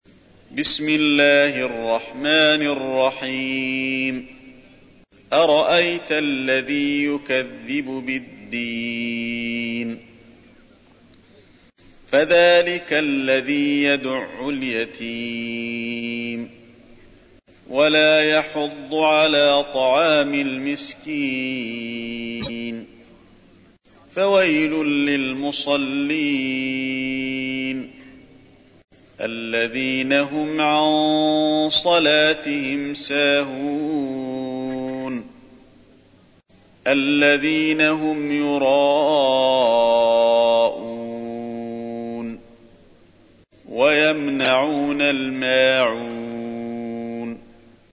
تحميل : 107. سورة الماعون / القارئ علي الحذيفي / القرآن الكريم / موقع يا حسين